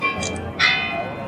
3) ¡Escucha! Este es el sonido de campanas de un cuarto; cuando sean "y media", sonarán dos cuartos ; y para "menos cuarto" sonará tres veces.
cuarto_torre.mp3